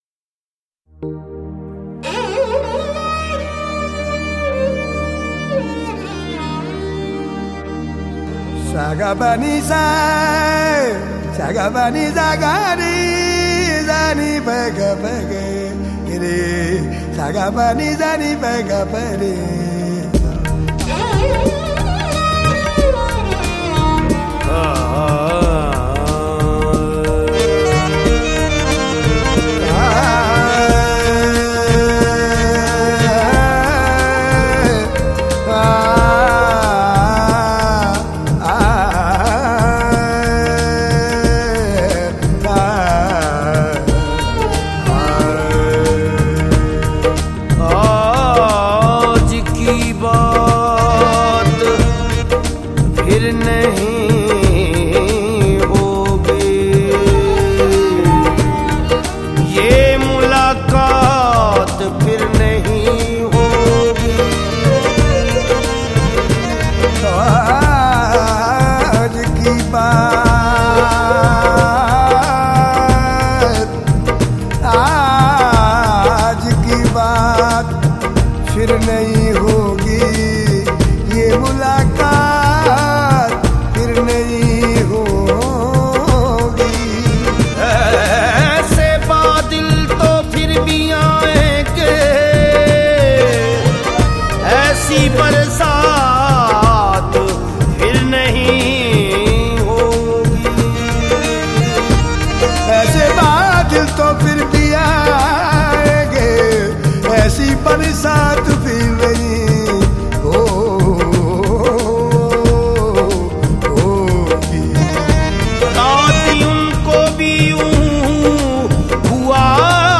powerful and magical voice